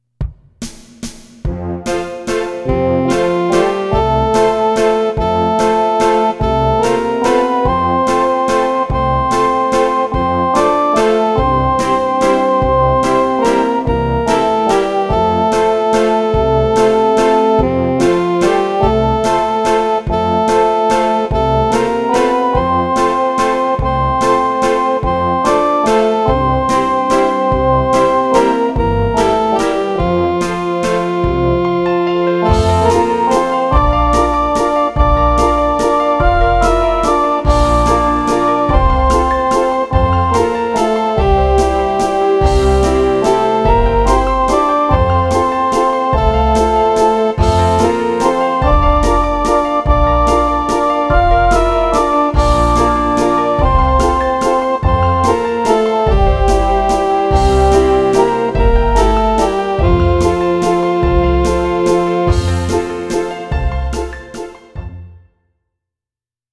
Flauta
Música: MIDI